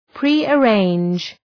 Προφορά
{,pri:ə’reındʒ}